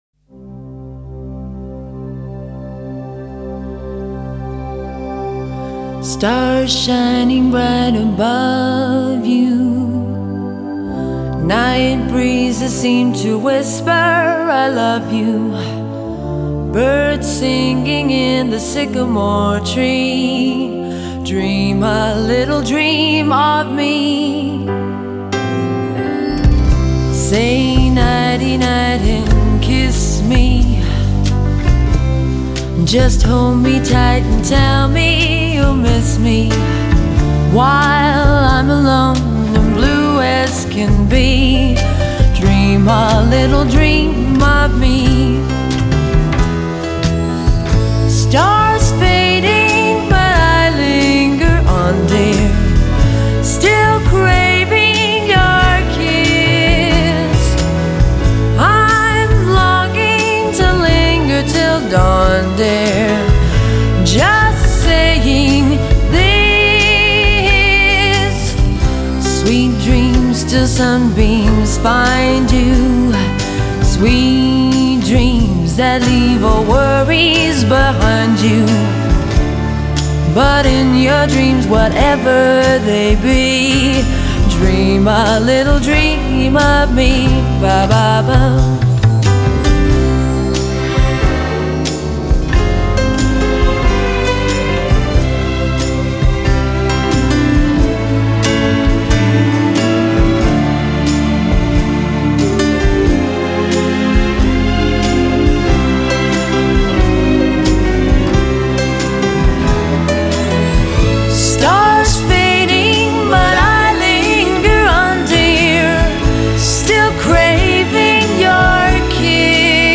★横跨日、韩、欧、美等音乐大厂 DSD数位录音发烧音质原曲原唱